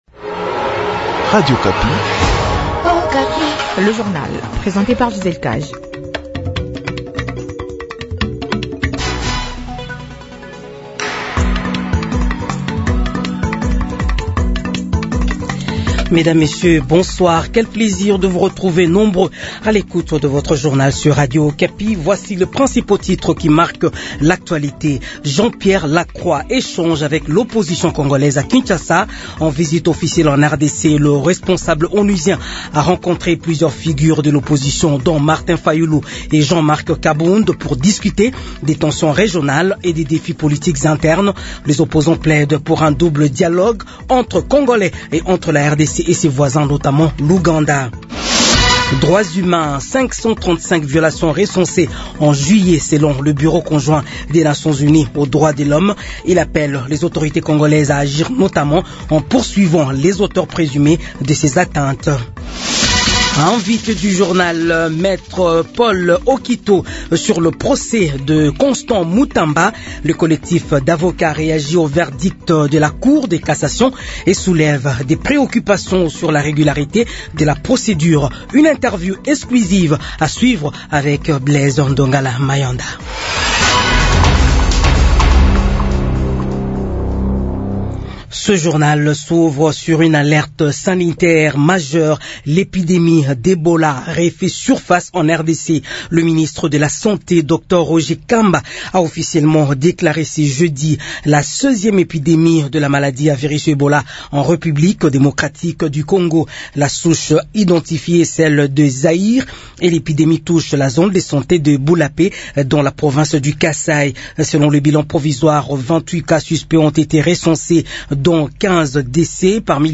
Journal 18h